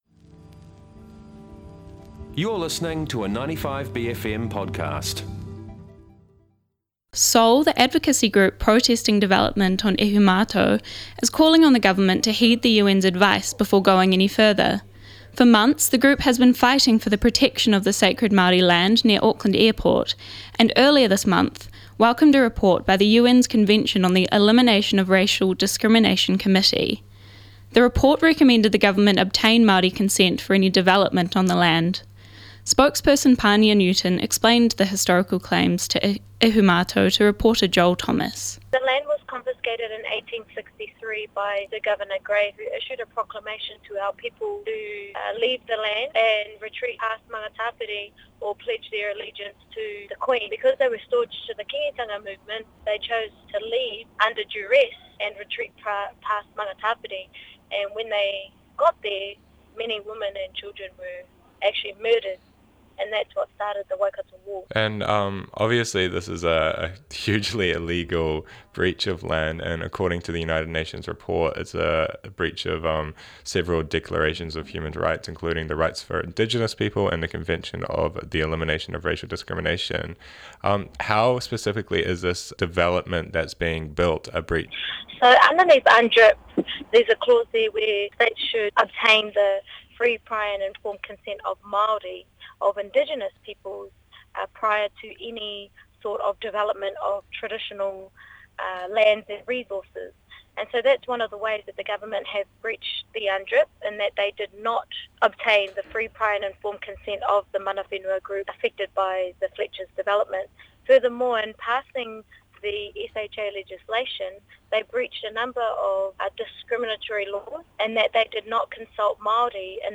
A dispute over development on sacred Māori land near Auckland Airport has resulted in the UN calling on the government to consult Māori first. Reporter